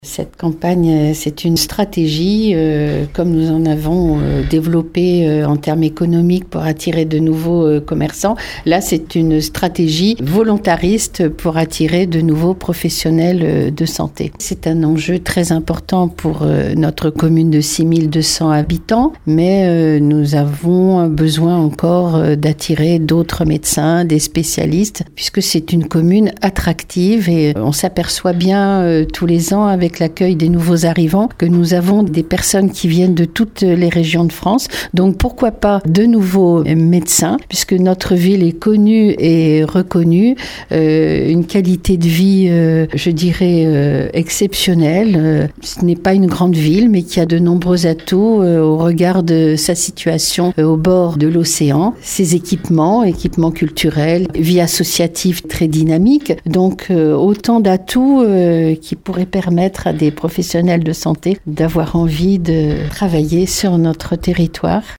Elle vient de lancer une campagne d’attractivité médicale intitulée « À Marennes, devenez la perle des soignants », avec en sous-titre « Et si votre carrière prenait vie dans la capitale de l’huître », histoire de bien assimiler le jeu de mots. L’enjeu est important pour le territoire qui, comme ailleurs, souffre d’un manque de professionnels de santé, comme le déplore la maire Claude Balloteau, qui a plus d’un argument pour séduire de nouveaux praticiens :